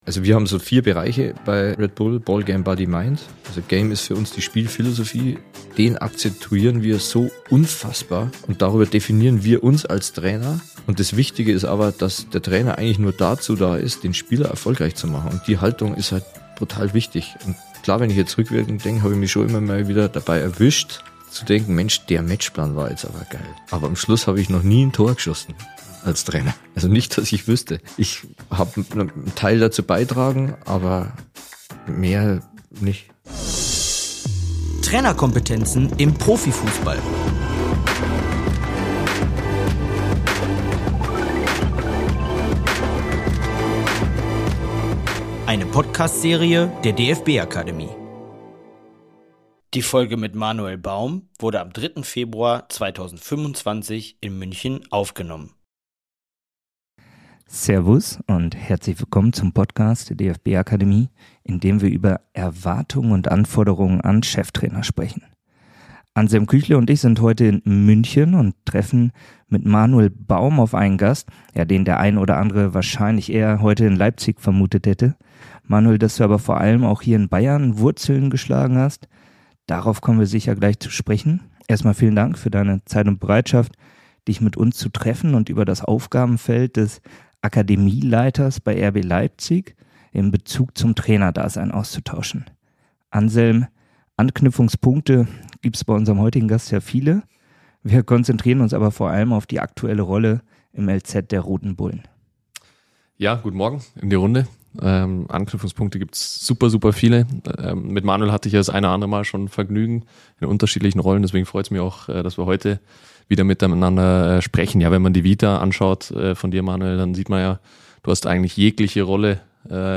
Der Podcast wurde am 17.1.2024 in Freiburg aufgenommen.